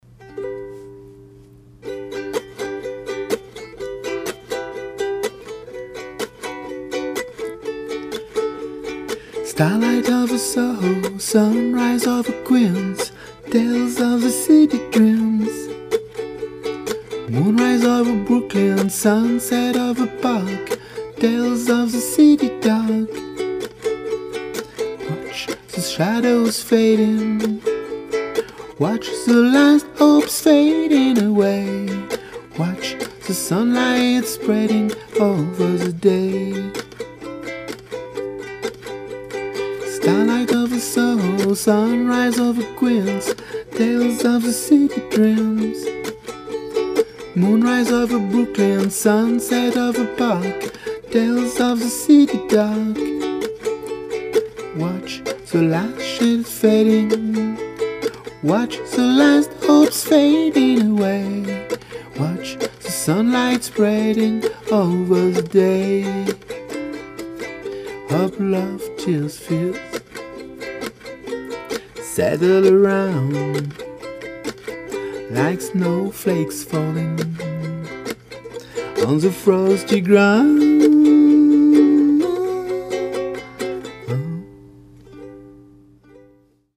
La maquette ukulele voix de ce mois ci n'a pas de paroles définitives, j'ai bricolé couplets/refrains à partir du texte de Woody Hammerstein Starlight over SoHo, j'ai dans l'idée d'ajouter un pont dans la version finale de ce titre, mais je ne me suis pas encore décidé sur la ligne mélodique, plusieurs versions enregistrées avec mon Zoom H2, mais rien d'arrêté.